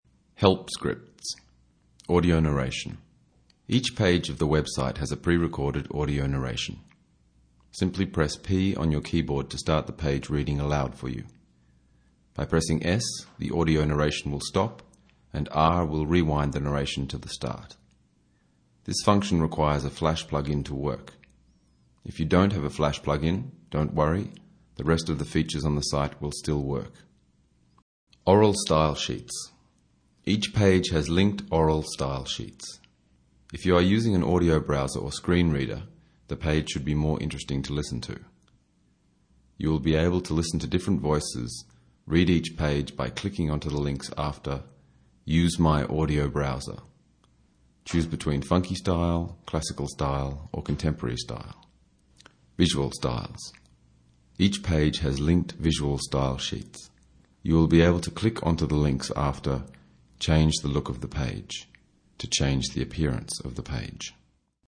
Audio narration, p = play, s = stop, r = rewind, f = fast forward, b = beginning, n = next page and h = home page.
Each page of the web site has a pre-recorded audio narration.